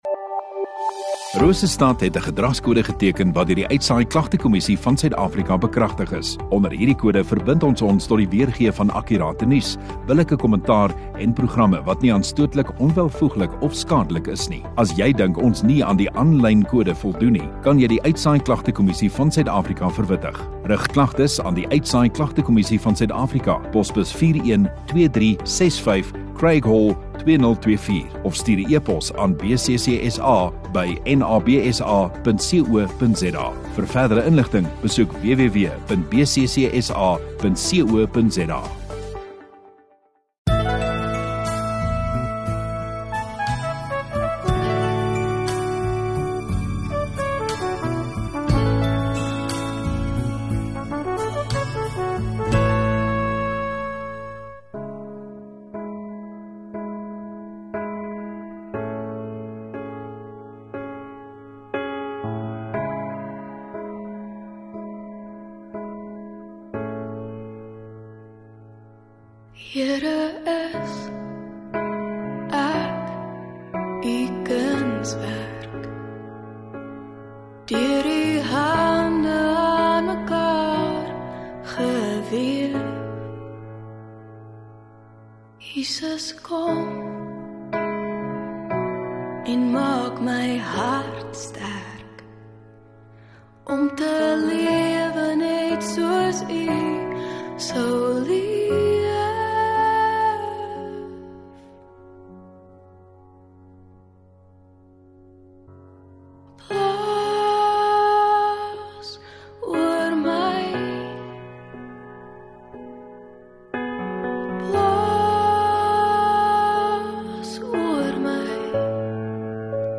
16 Mar Sondagaand Erediens